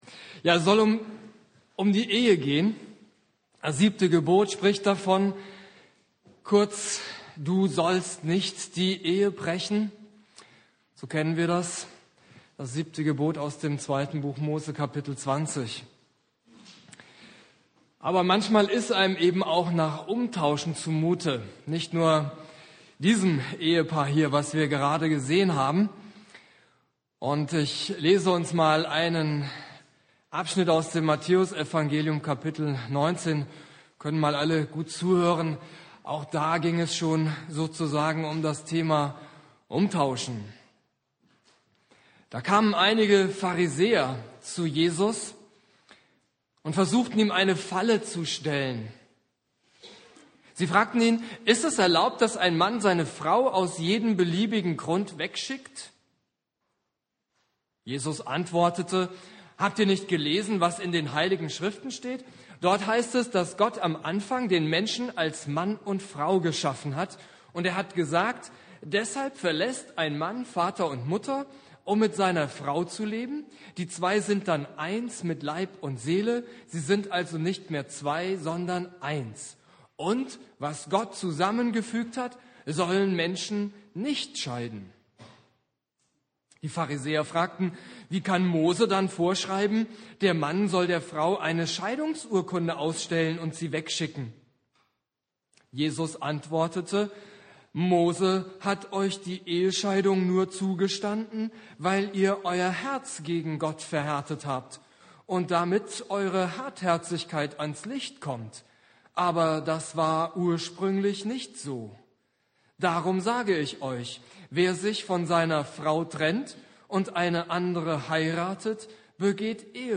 Predigt
Ewigkeitssonntag Prediger